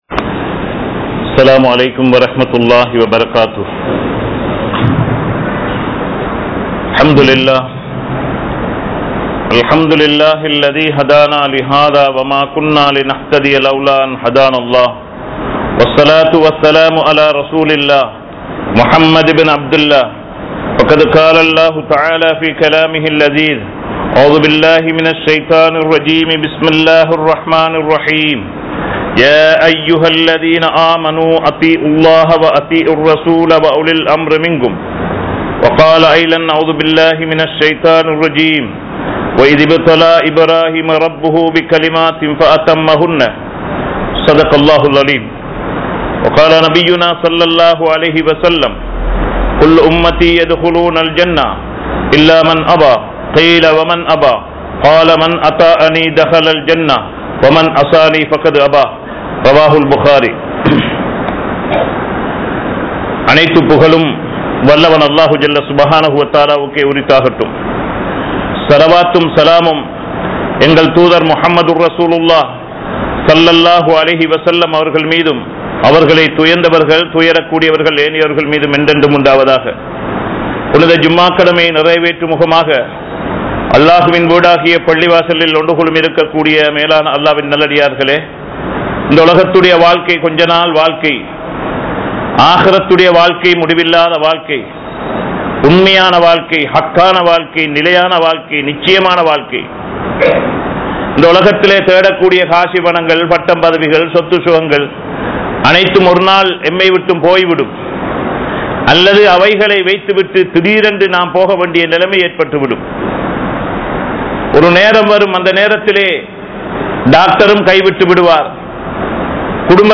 Allah`vukku Kattup Padungal(அல்லாஹ்வுக்கு கட்டுப்படுங்கள்) | Audio Bayans | All Ceylon Muslim Youth Community | Addalaichenai
Colombo 06,Kirulapana, Thaqwa Jumua Masjith